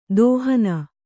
♪ dōhana